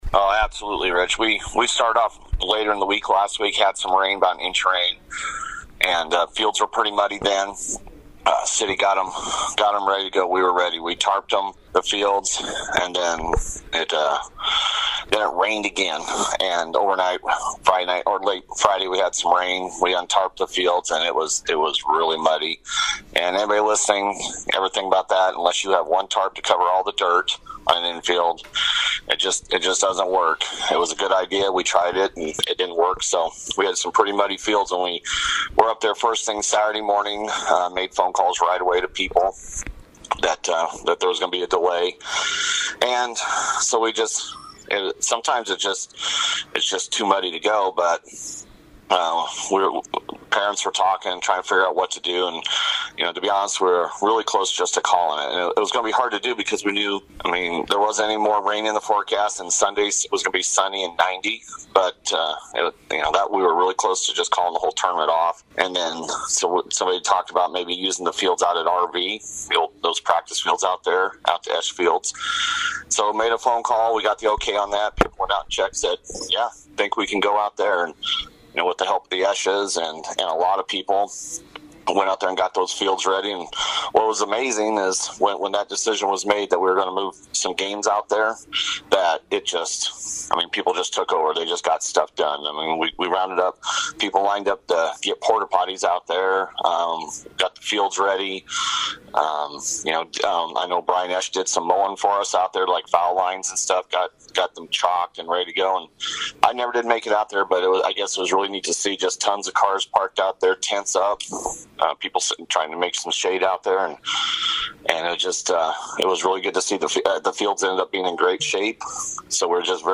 INTERVIEW: Rebels 16s wrap up summer schedule with Firecracker championship.